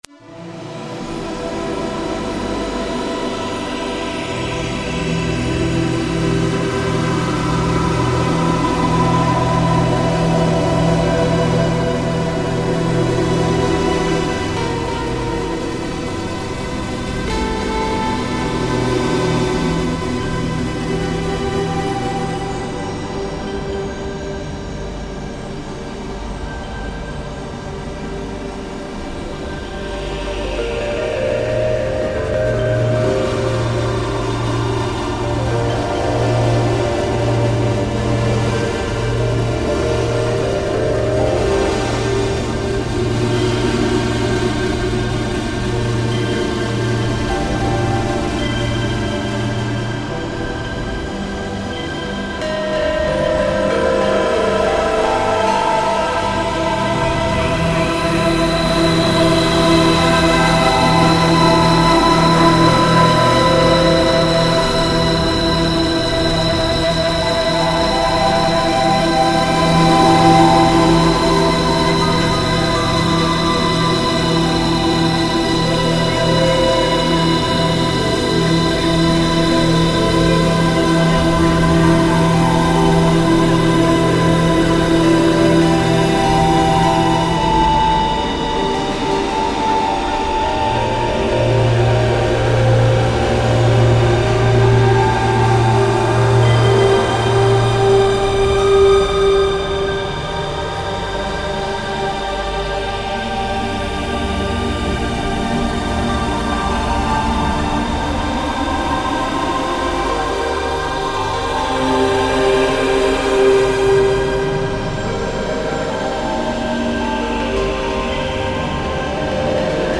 File under: Ambient / Avantgarde